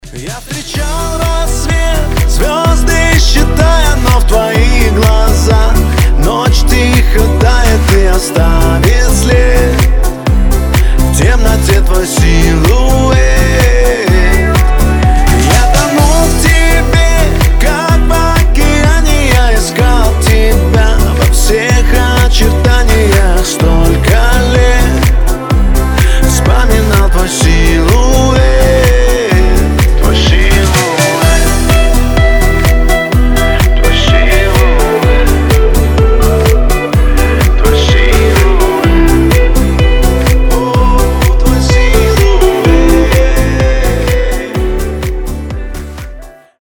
• Качество: 320, Stereo
гитара
мужской голос